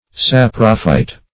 Saprophyte \Sap"ro*phyte\, n. [Gr. sapro`s rotten + fyto`n a